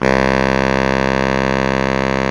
SAX B.SAX 0Y.wav